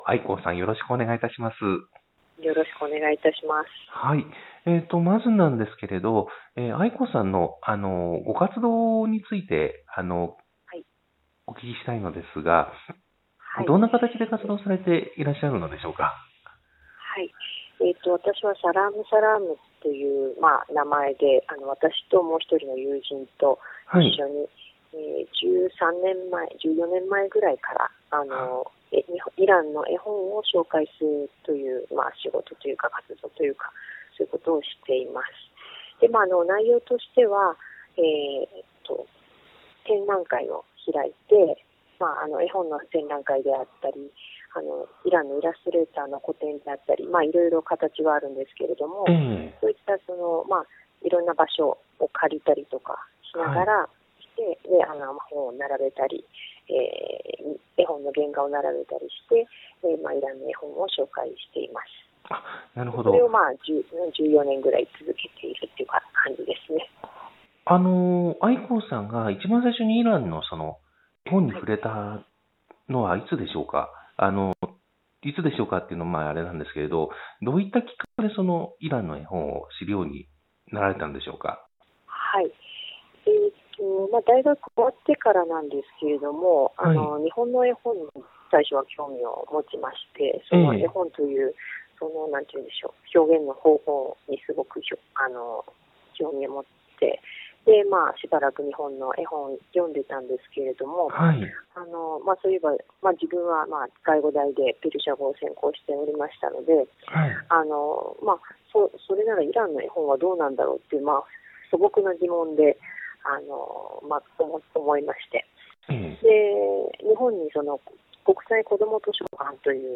インタビュー